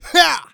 XS发力06.wav
XS发力06.wav 0:00.00 0:00.54 XS发力06.wav WAV · 47 KB · 單聲道 (1ch) 下载文件 本站所有音效均采用 CC0 授权 ，可免费用于商业与个人项目，无需署名。
人声采集素材